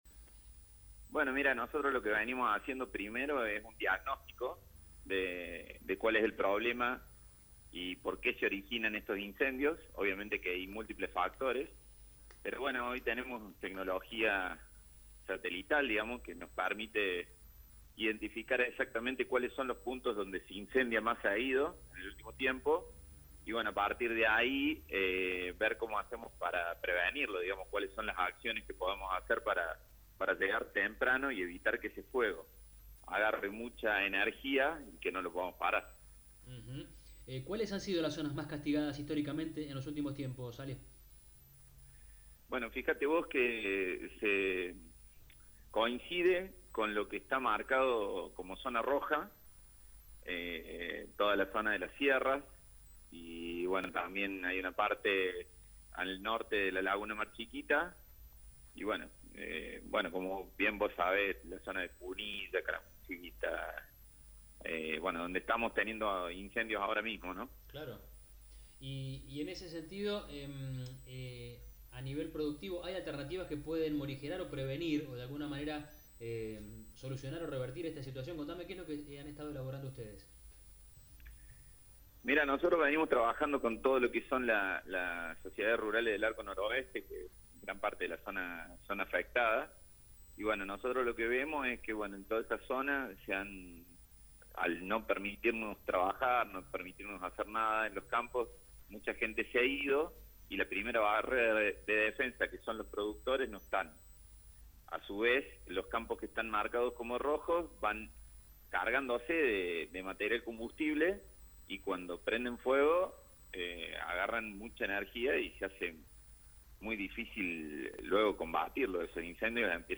En diálogo con El Campo Hoy